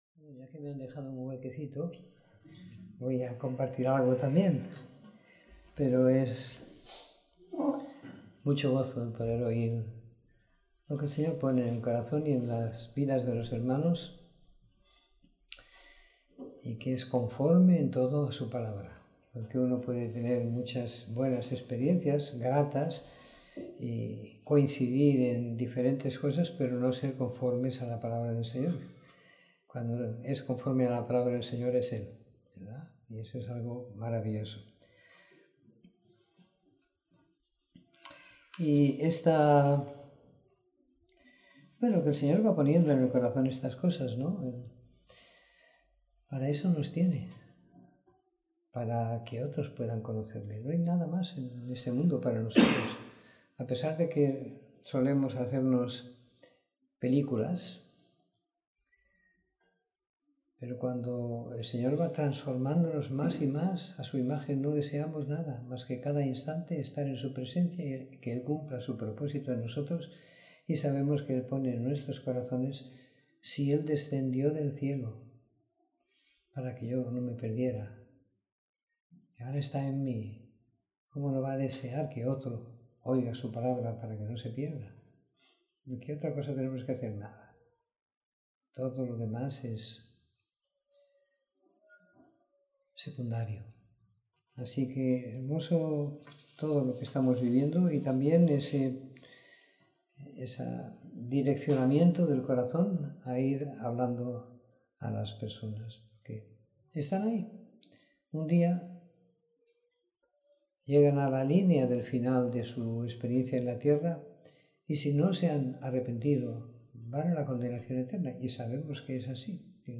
Reunión de Domingo por la Tarde